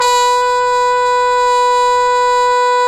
Index of /90_sSampleCDs/Roland L-CD702/VOL-2/BRS_Cup Mute Tpt/BRS_Cup Mute Dry